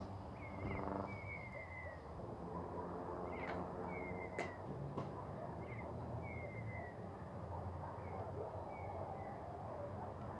Colorada (Rhynchotus rufescens)
Nombre en inglés: Red-winged Tinamou
Condición: Silvestre
Certeza: Observada, Vocalización Grabada